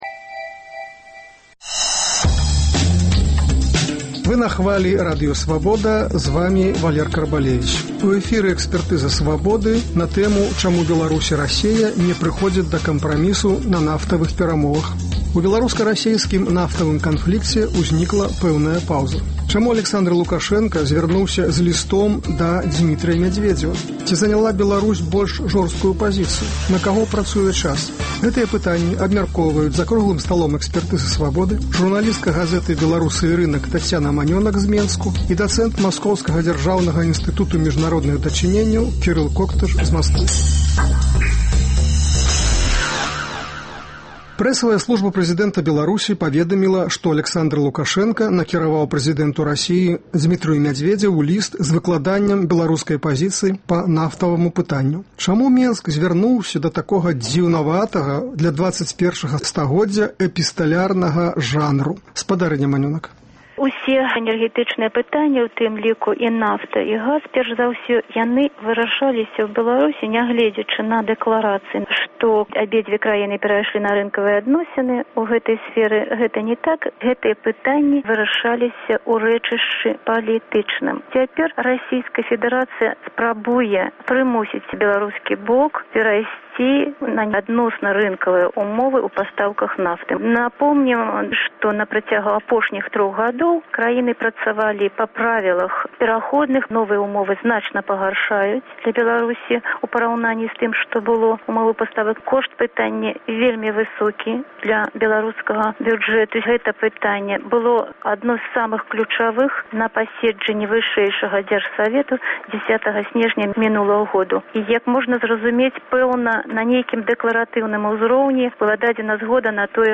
Гэтыя пытаньні абмяркоўваюць за круглым сталом